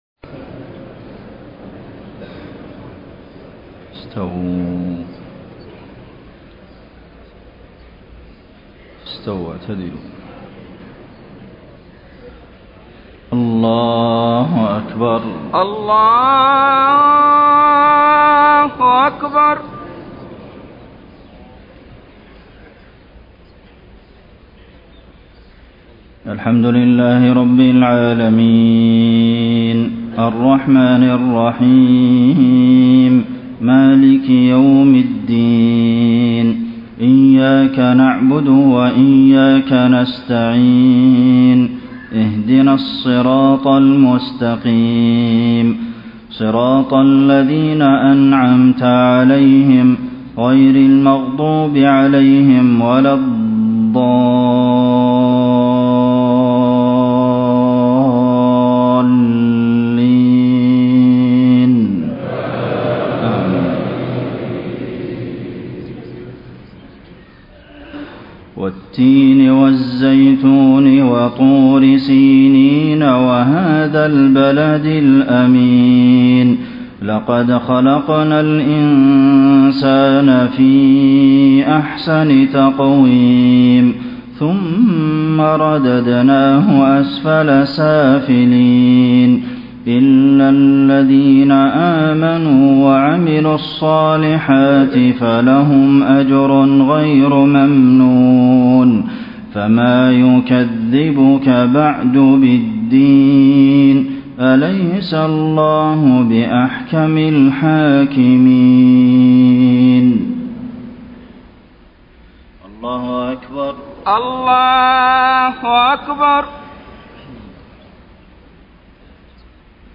صلاة المغرب 8 - 4 - 1434هـ سورتي التين و الهمزة > 1434 🕌 > الفروض - تلاوات الحرمين